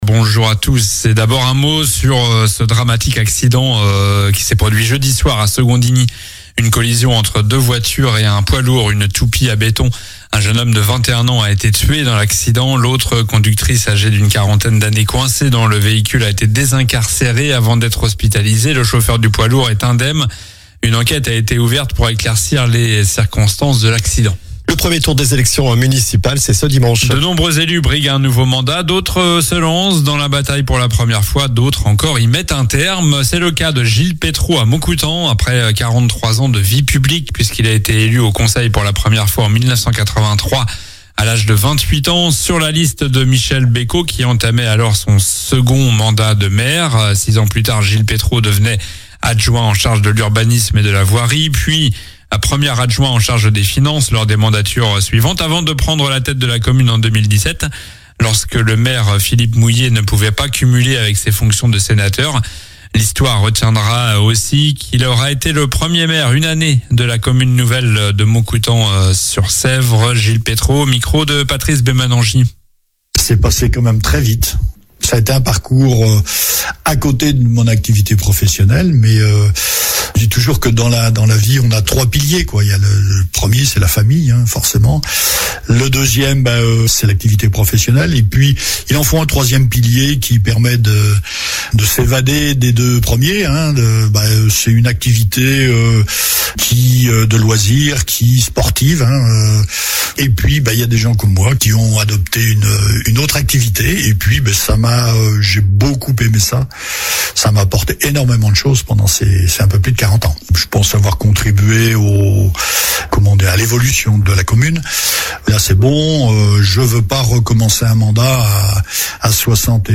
Journal du samedi 14 mars 8h